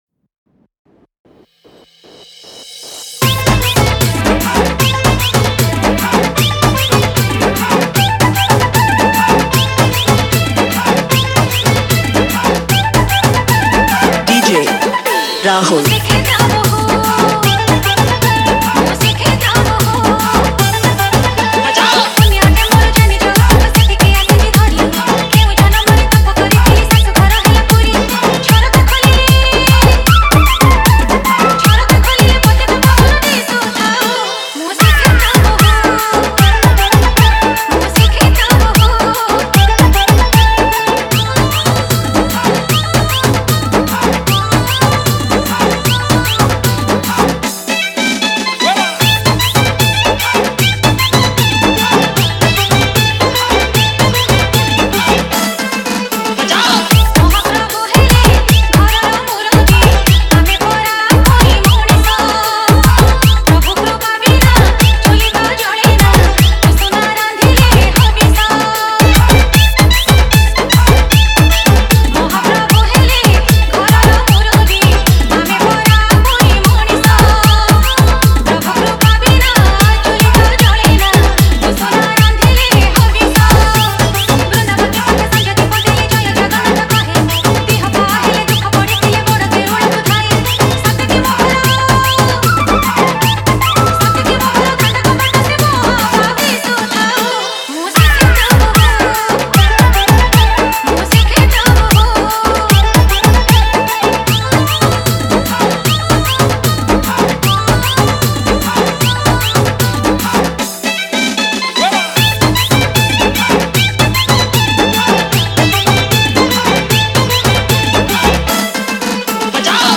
Category:  Odia Bhajan Dj 2019